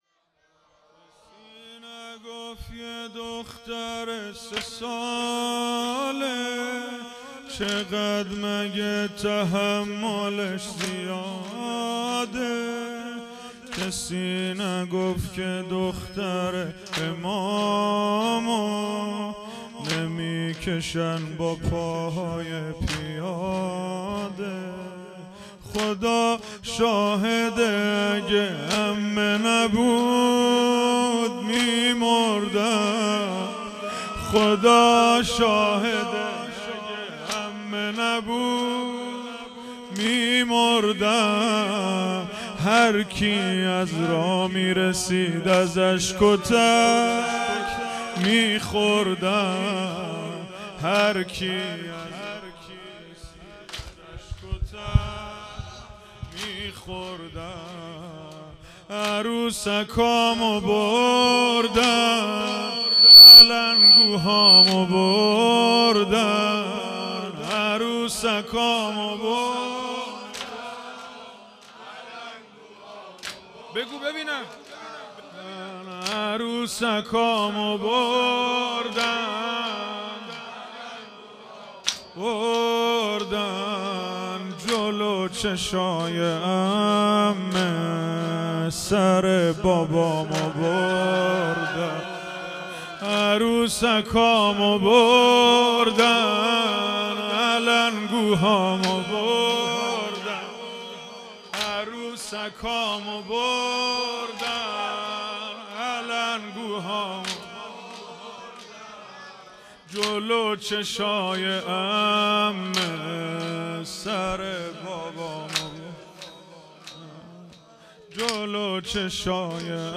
دهه اول محرم الحرام ۱۴۴۴